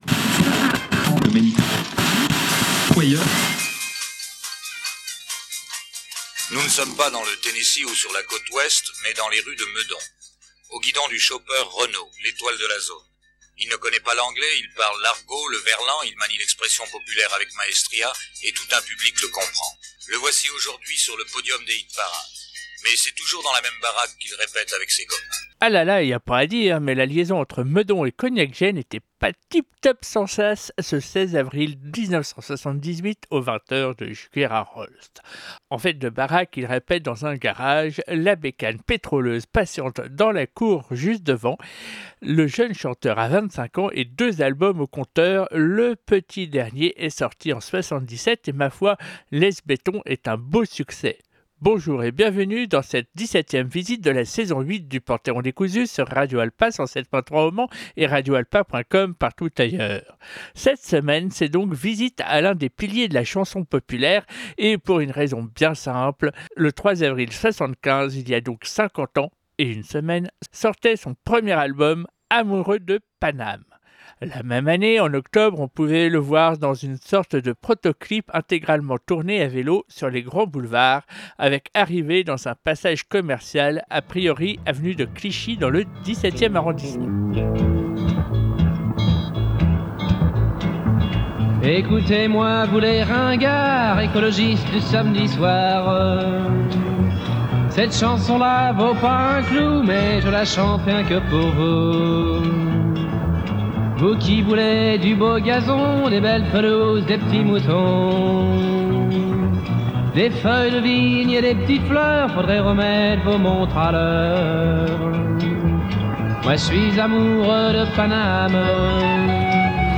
De la guitare et de l’accordéon, du macadam des grands boulevards, des rimes riches, un garage, des deux roues…